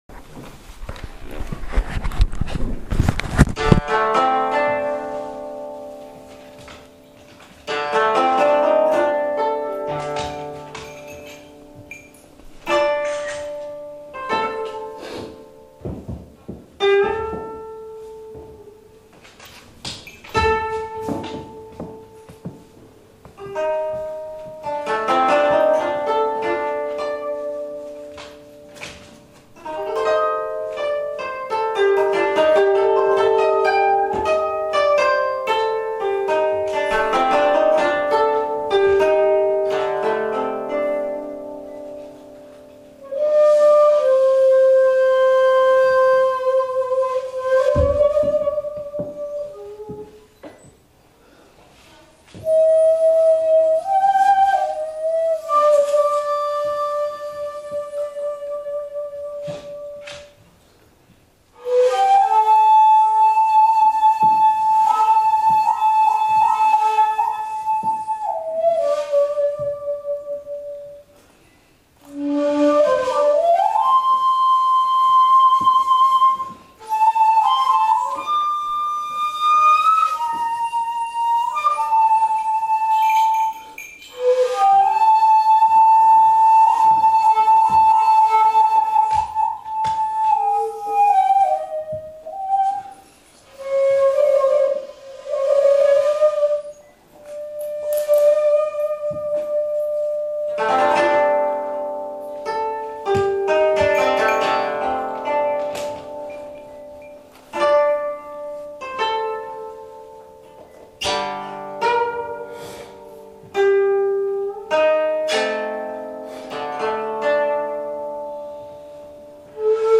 秋の穏やかな天気に恵まれた小さな演奏会でした。
会場は大阪府泉佐野市の文化財住宅(江戸後期）・新川家で尺八のような邦楽器にふさわしい場所でした。
○「惜別之舞」（宮田耕八朗作曲　地無し管）